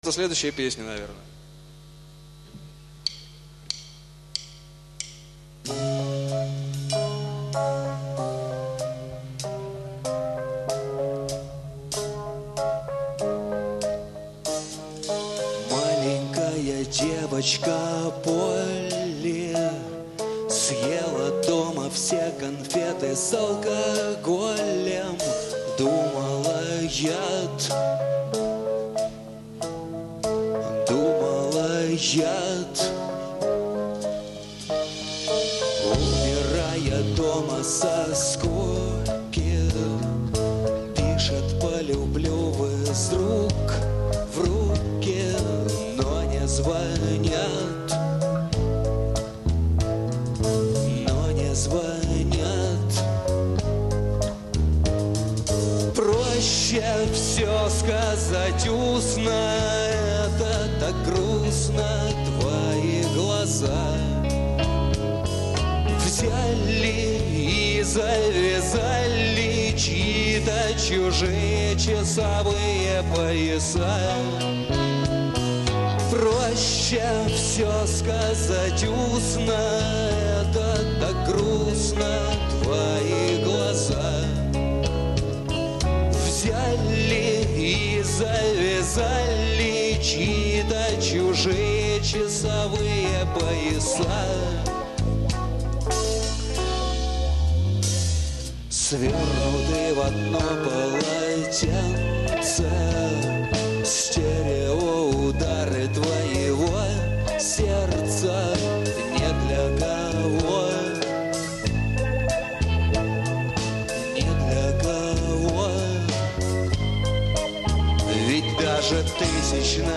рок-группы
Запись через линейный вход
mono.